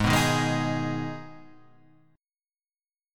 G#m chord